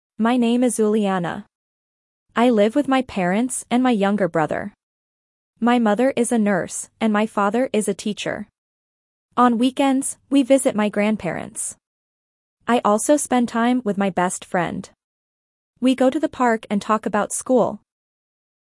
Dictation A2 - Family and Friends